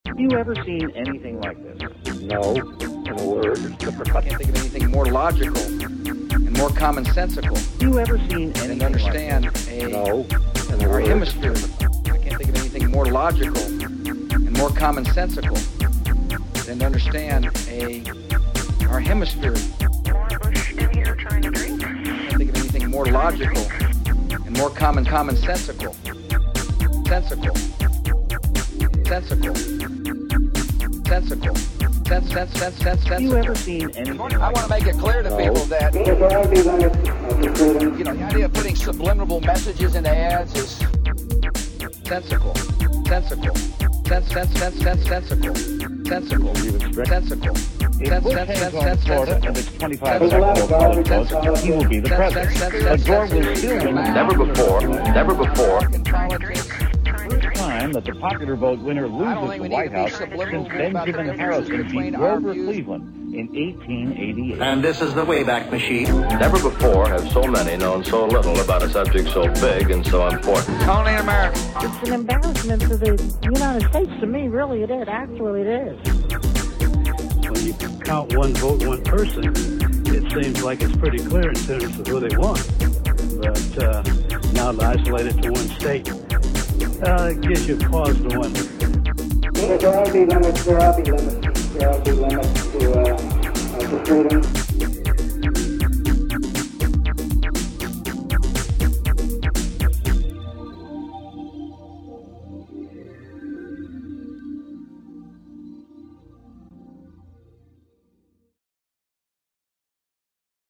Creator: The Evil Calicoes
Further play on mispronunciation.